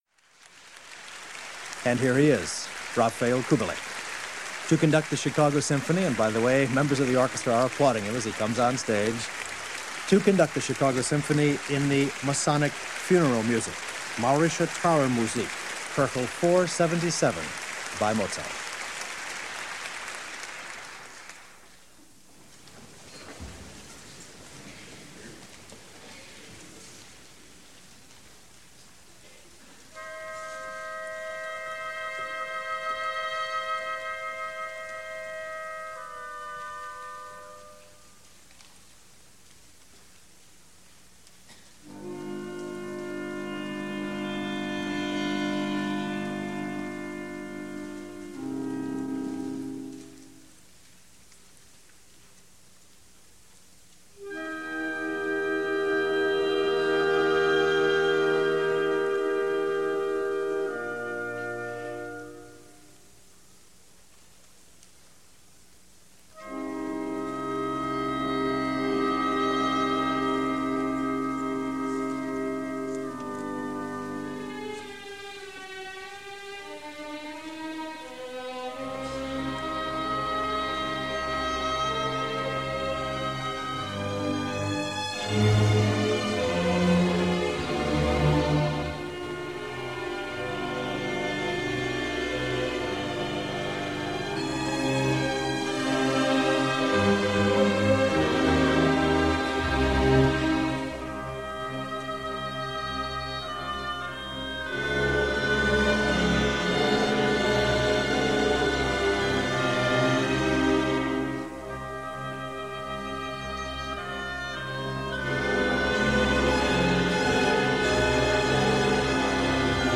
The concert was broadcast on November 8, 1980.